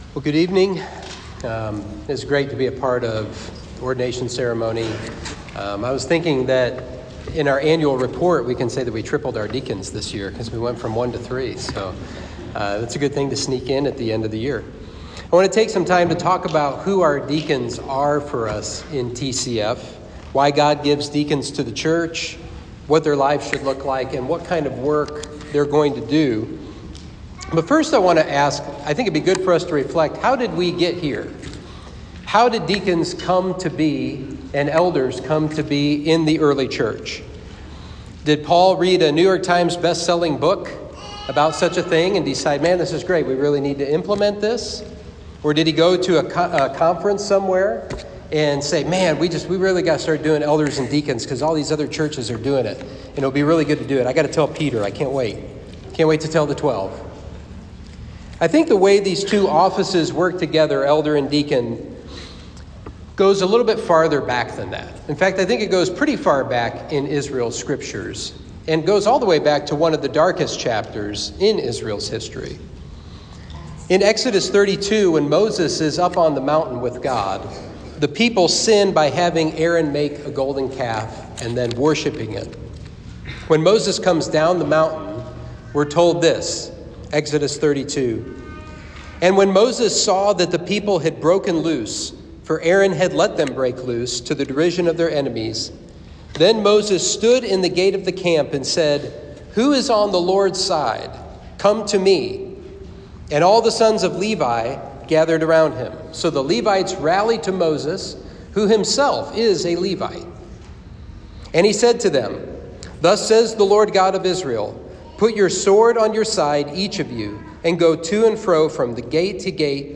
Sermon 12/13: Deacons: Sons of Encouragement